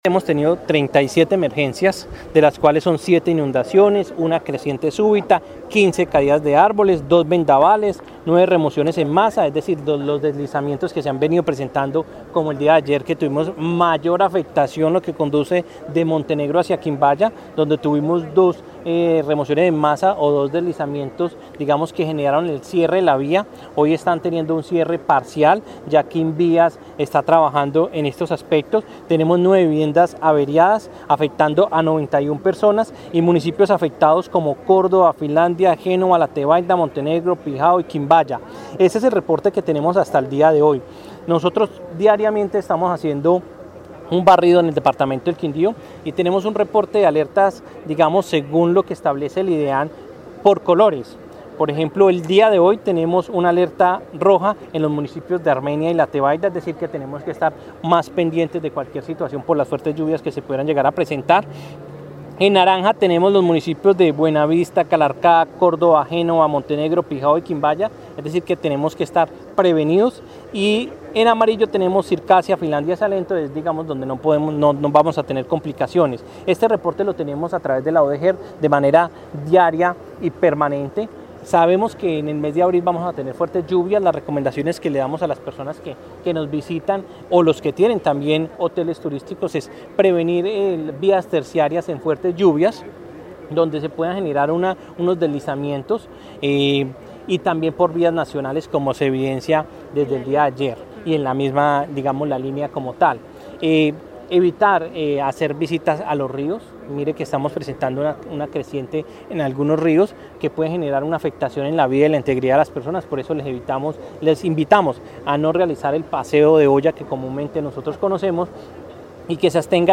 Secretario del Interior del Quindío sobre las emergencias